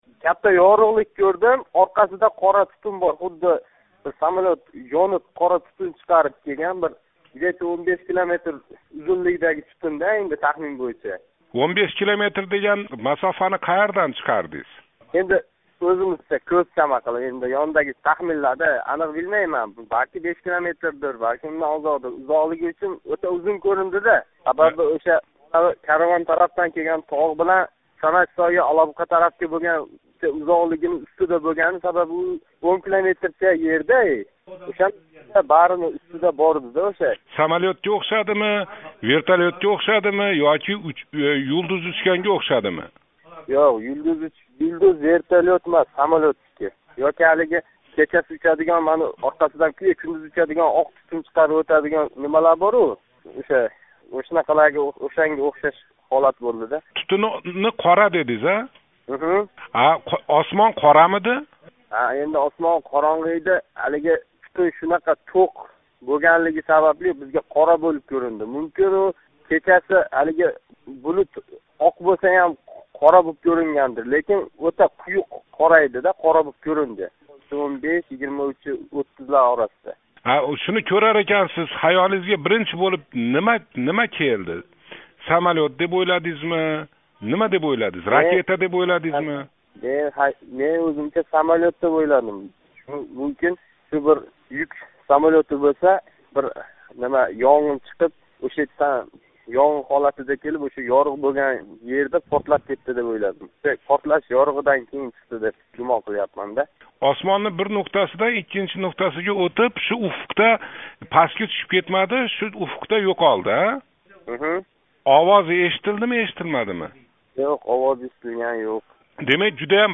Олабуқалик тингловчи билан суҳбат